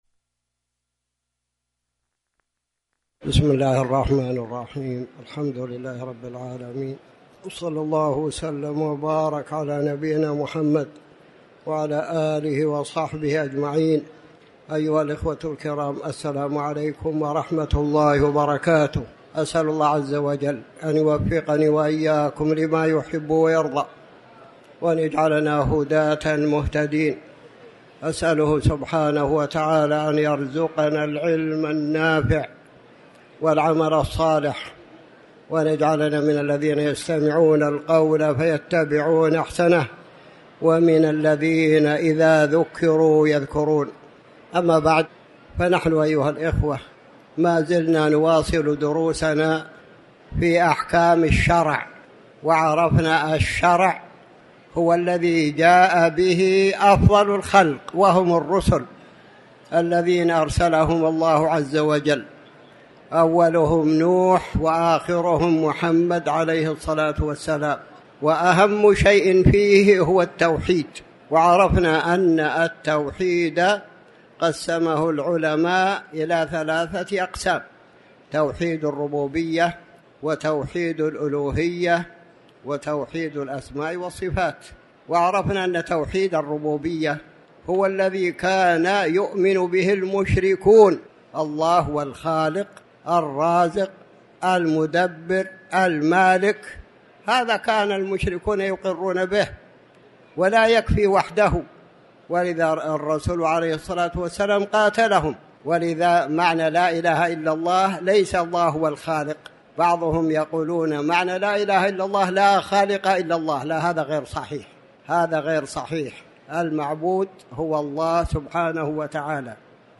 تاريخ النشر ٢٥ ربيع الثاني ١٤٤٠ هـ المكان: المسجد الحرام الشيخ